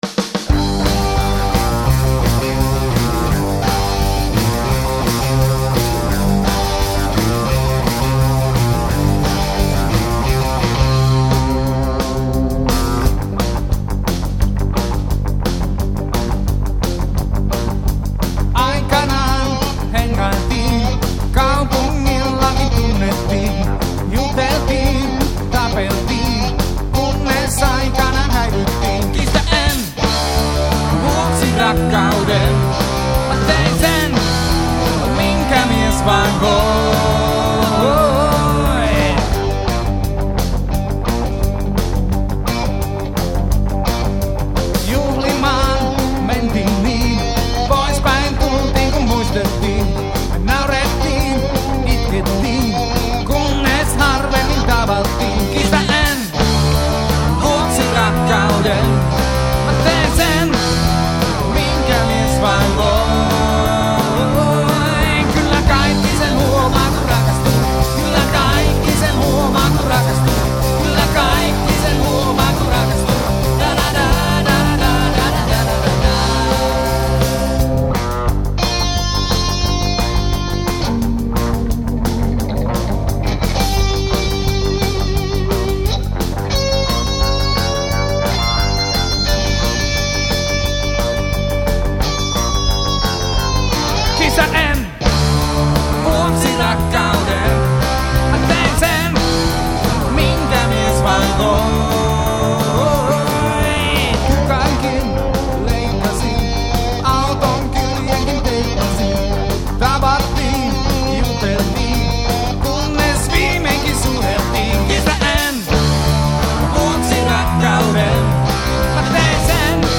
Live!